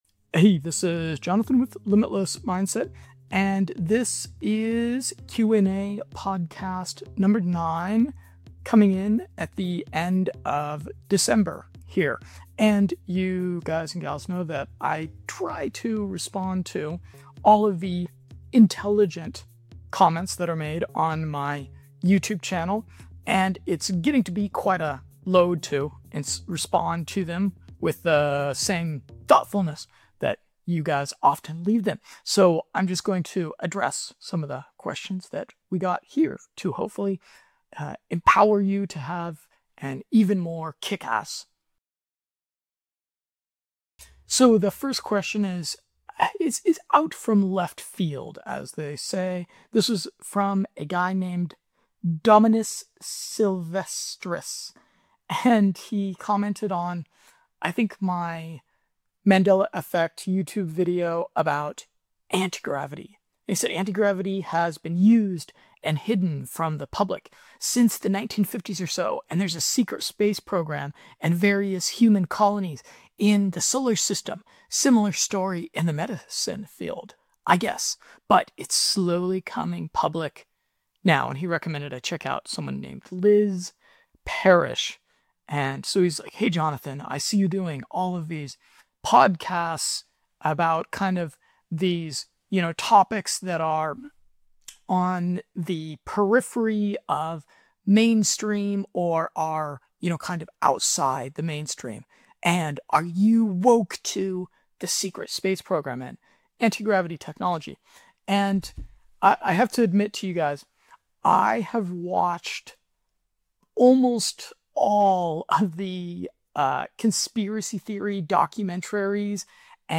Best Nootropics for Musicians & DJs, Piracetam Protocol Questions, the WORST Philosopher & More 🎼 January Biohacking Q&A #22